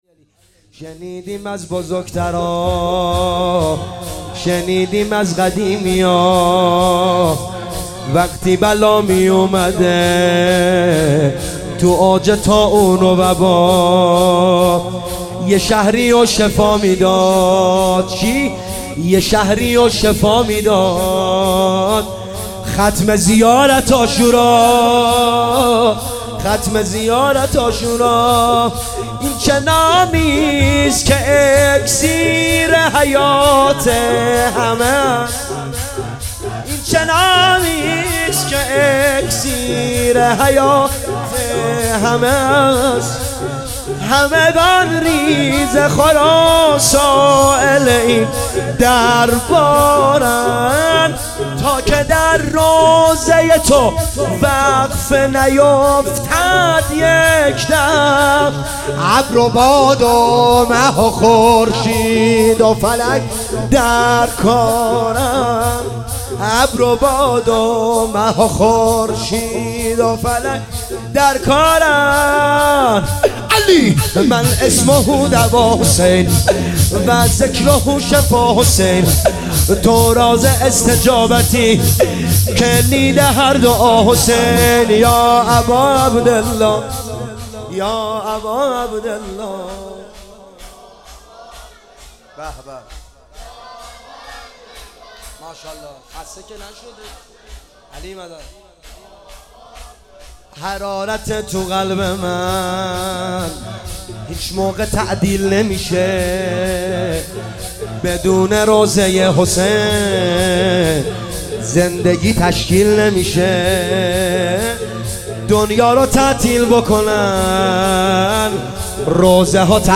شهادت امام هادی (ع)